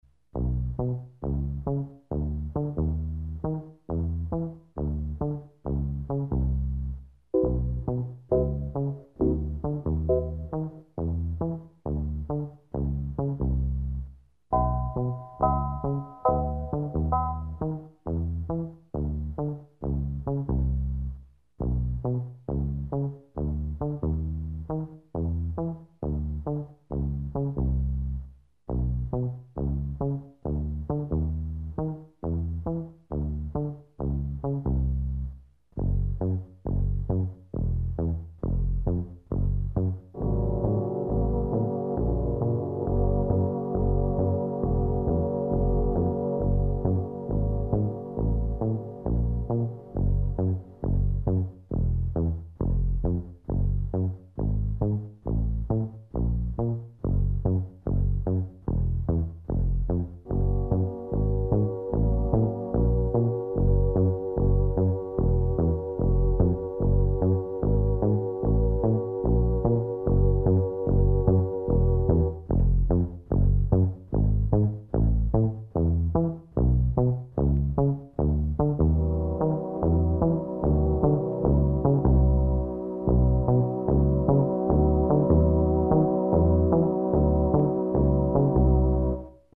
radostné, povznášející rytmy
Verbální vedení: Neverbální